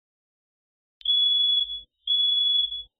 Free SFX sound effect: Tire Screech.
Tire Screech
565_tire_screech.mp3